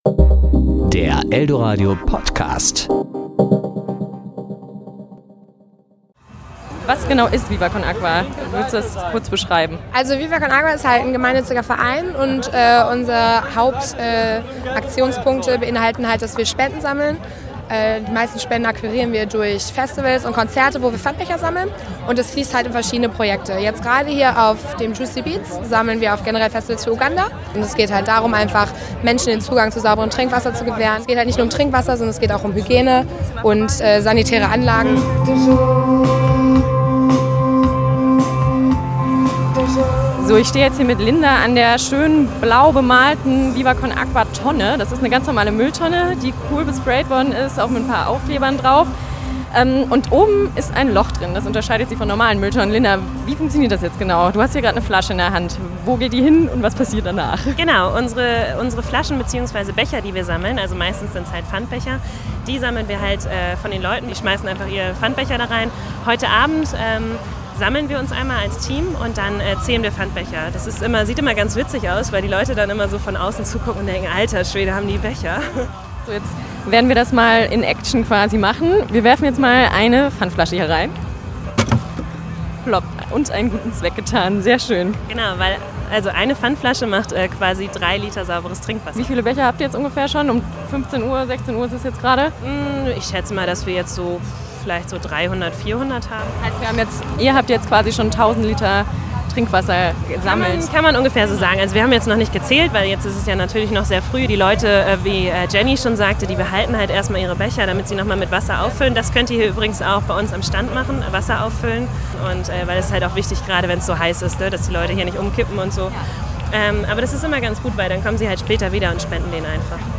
Serie: Beiträge  Ressort: Wort  Sendung: Toaster